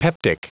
Prononciation du mot peptic en anglais (fichier audio)
Prononciation du mot : peptic